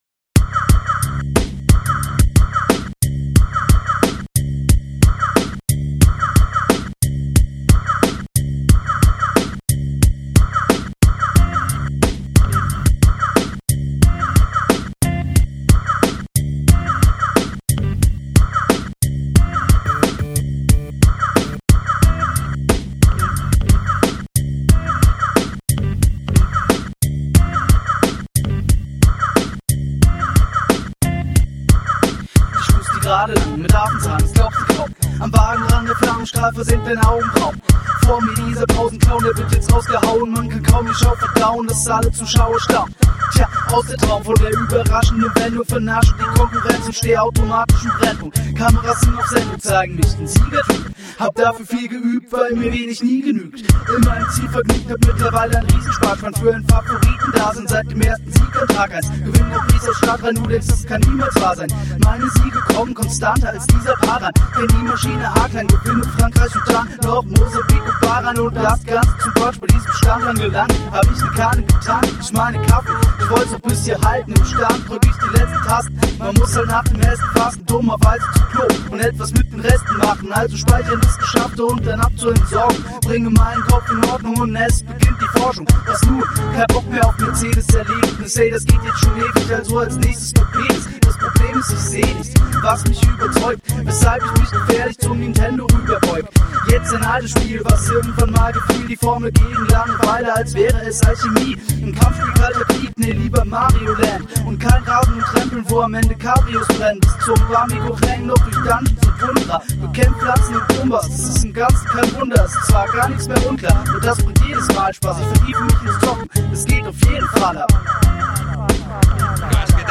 HipHop project